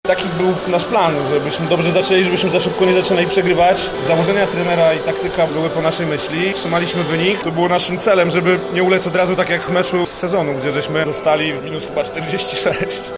-mówi zawodnik gości